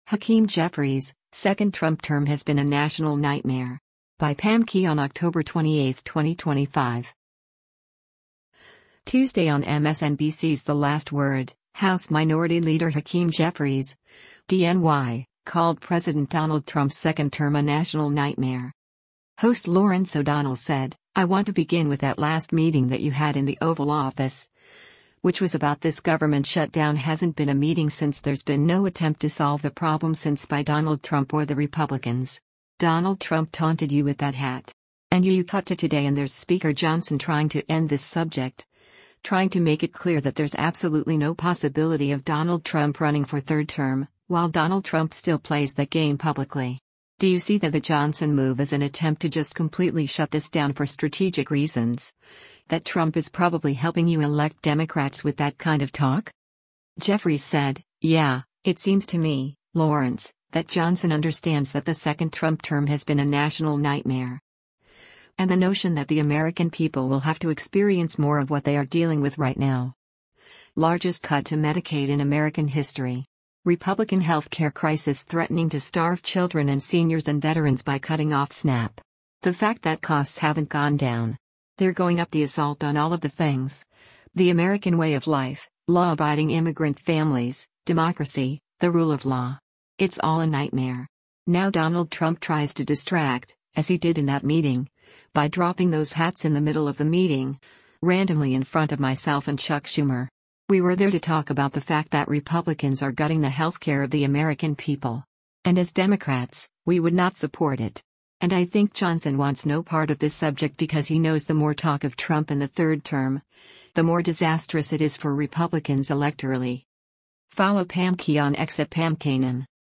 Tuesday on MSNBC’s “The Last Word,” House Minority Leader Hakeem Jeffries (D-NY) called President Donald Trump’s second term a “national nightmare.”